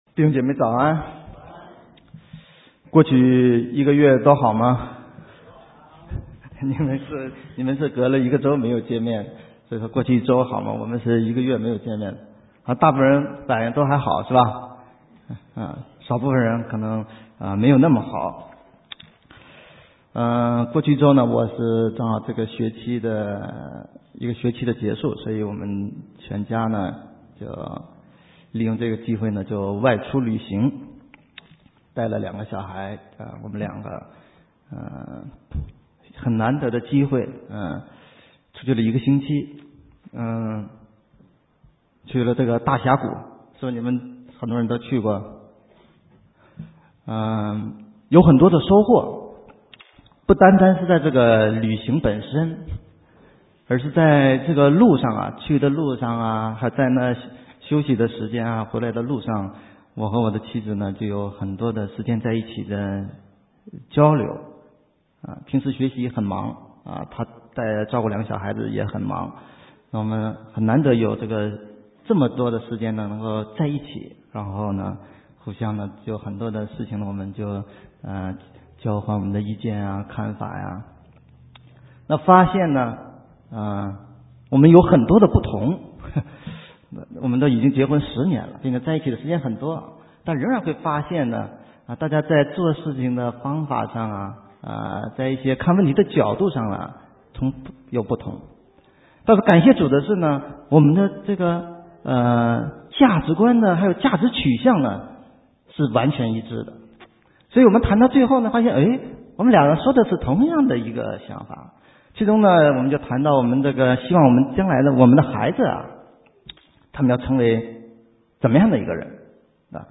神州宣教--讲道录音 浏览：羡慕善工 (2010-03-28)